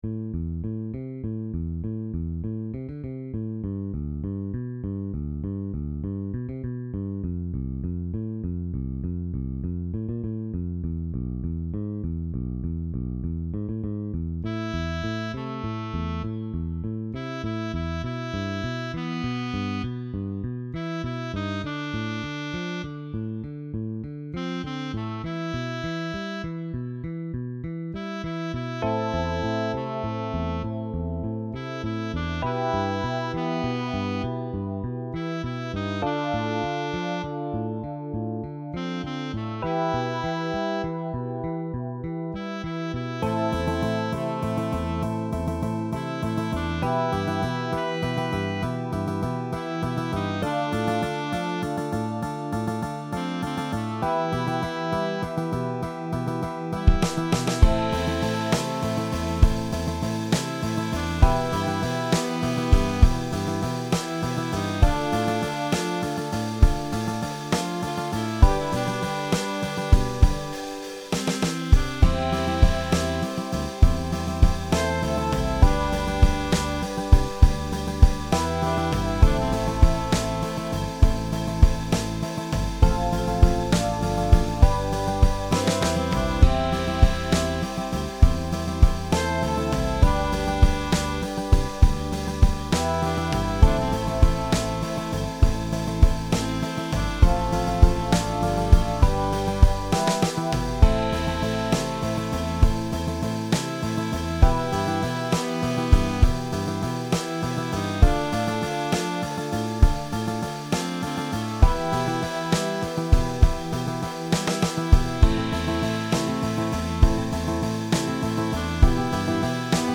BluesBallad
보컬 파트는 전반부는 색소폰이고 후반부는 비브라폰입니다.